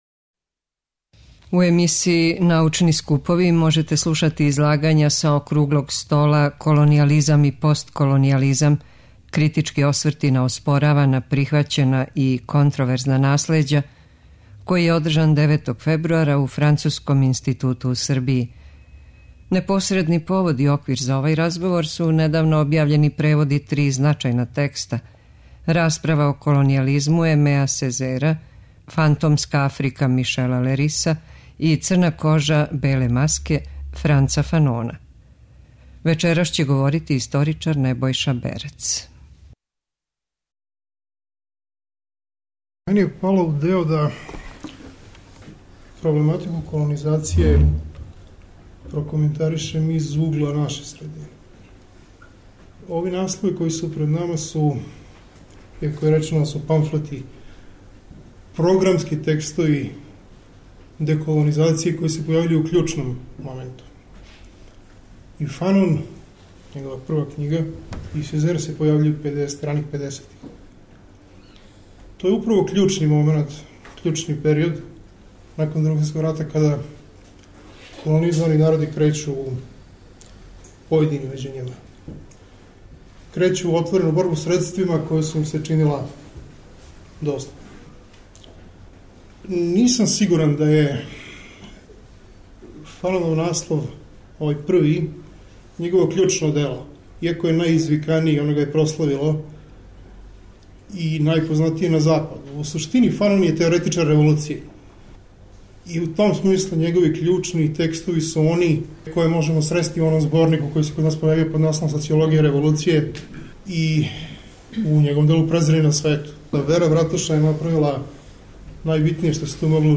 преузми : 10.21 MB Трибине и Научни скупови Autor: Редакција Преносимо излагања са научних конференција и трибина.